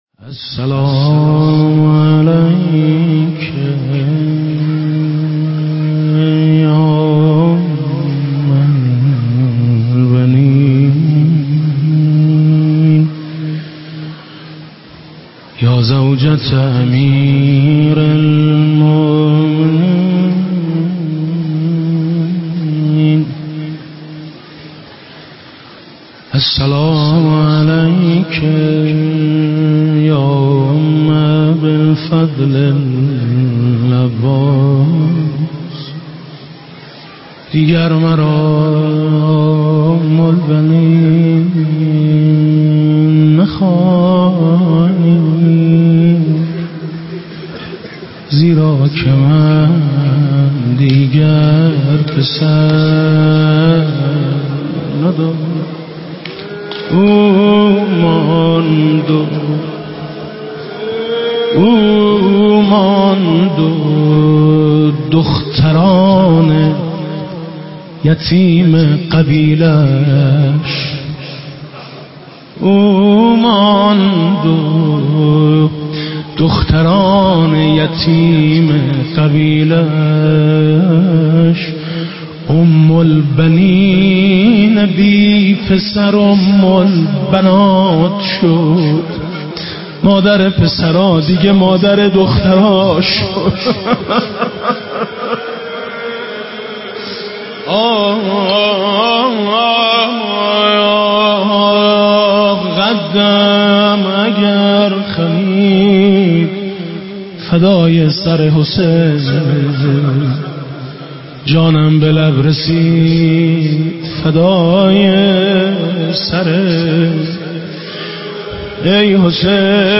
روضه و توسل شهادت حضرت اُم البنین سلام الله علیها به نفسِ حاج ميثم مطيعي -(دیگر مرا ام البنین نخوانید)